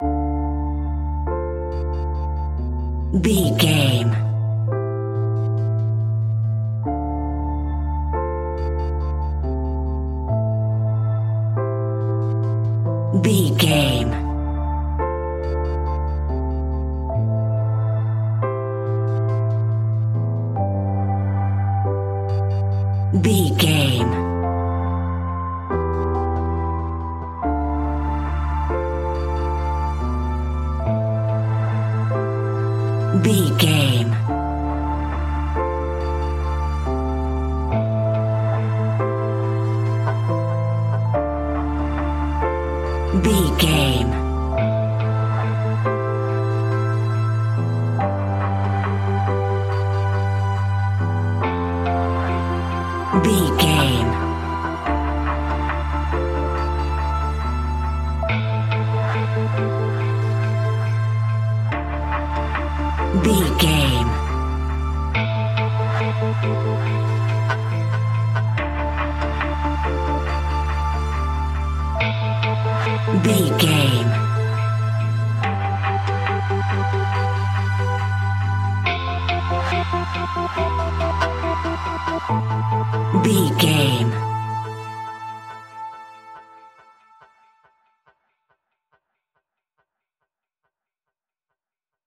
A modern and stylish atmospheric track.
Uplifting
Aeolian/Minor
Slow
ambient
dreamy
ethereal
melancholy
percussion
synthesiser